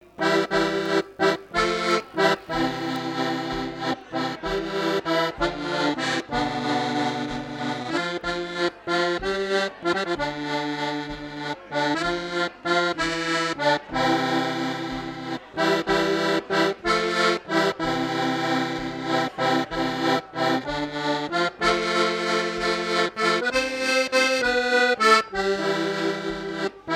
danse : valse
Fête de l'accordéon
Pièce musicale inédite